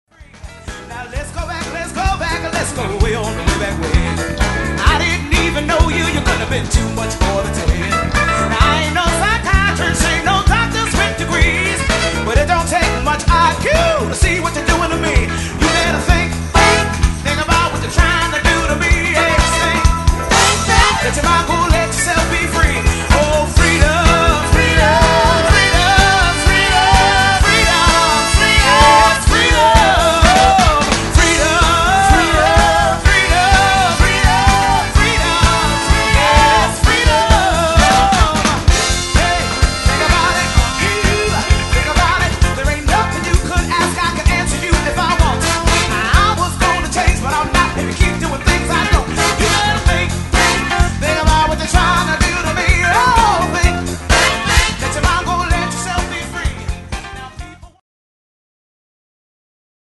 Trombone
El.bass
Div. keyboards
Drums
E. Fem.